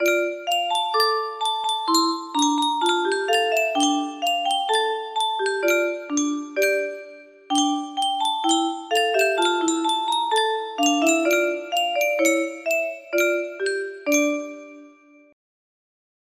Yunsheng Soittorasia - Niin minä neitonen Sinulle laulan 1265 music box melody
Full range 60